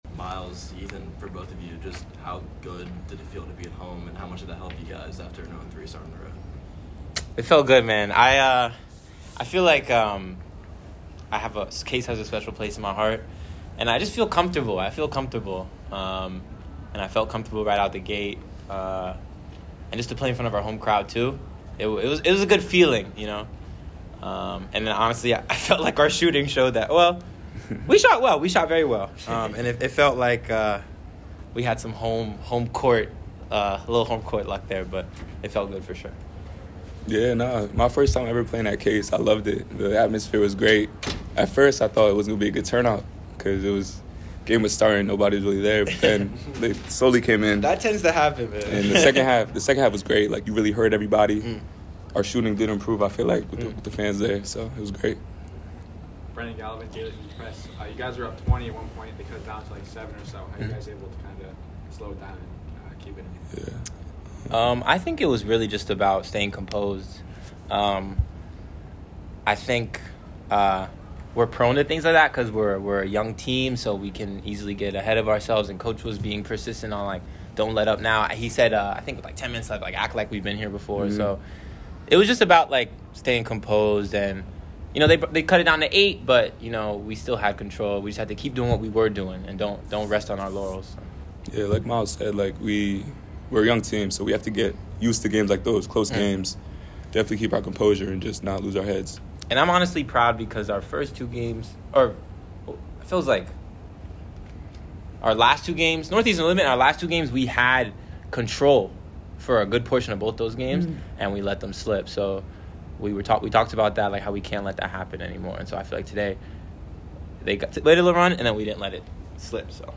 Men's Basketball / Bryant Postgame Interview (11-16-23)